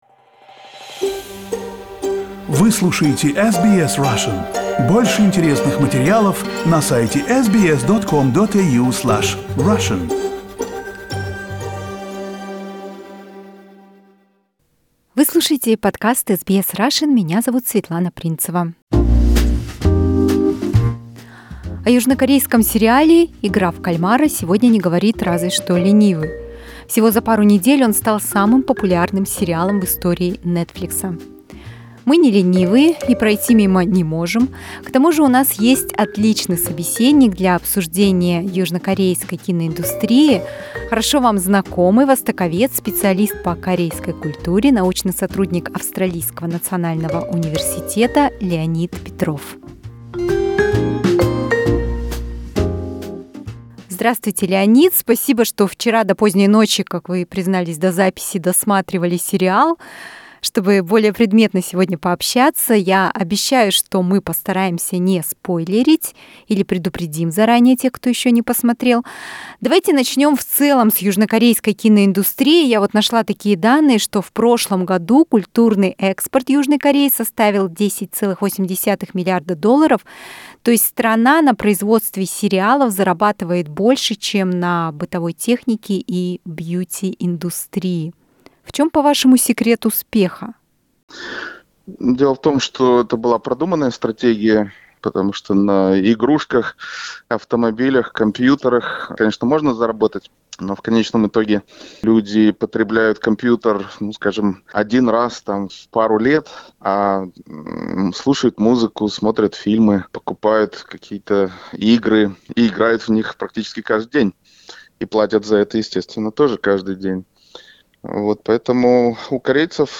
* Правильное название одного из рекомендованных в интервью южнокорейских фильмов JSA (не DMZ), «Joint Security Area».